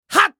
熱血系ボイス～戦闘ボイス～
【攻撃（弱）1】